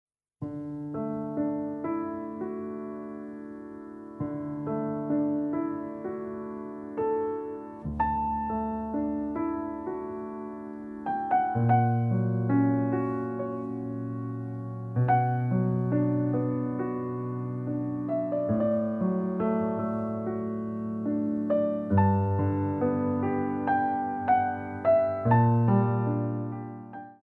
Adage